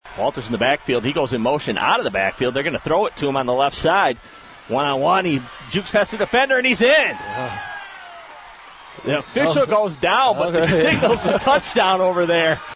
Audio from WKHM’s radio broadcast